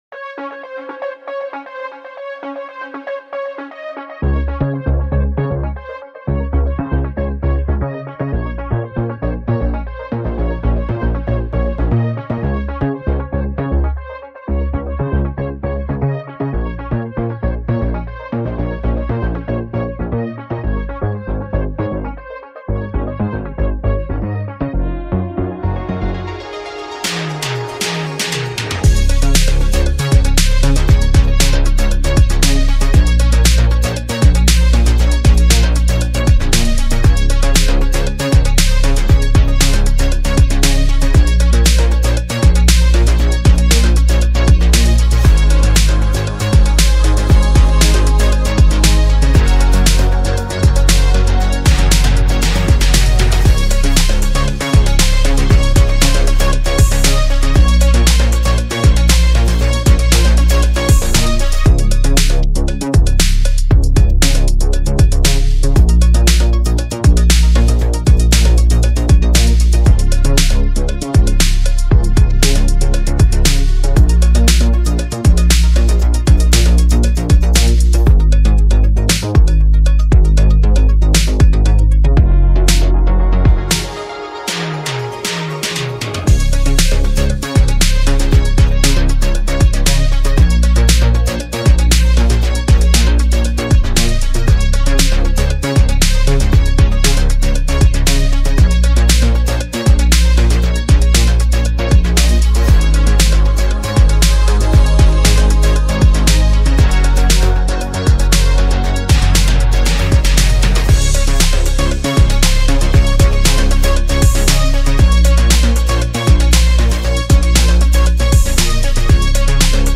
This is the instrumental of the new song.